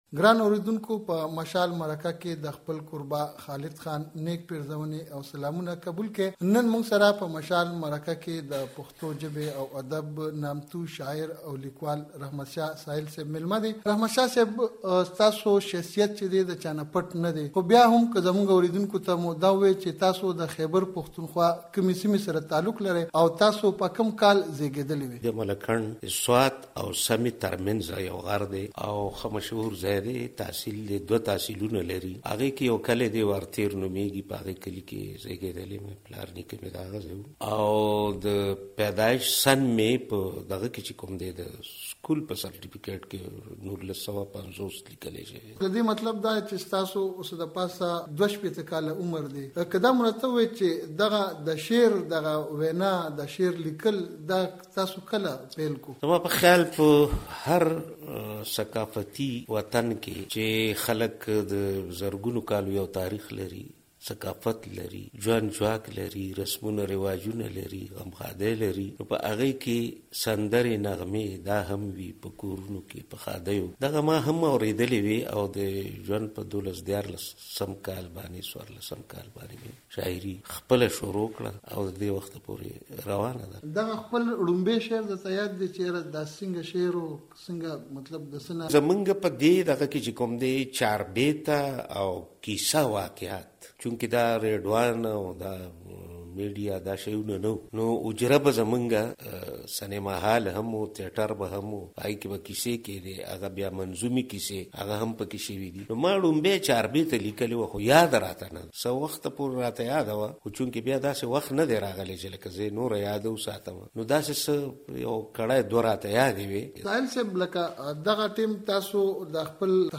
رحمت شا سایل د مشال سره مرکه کوي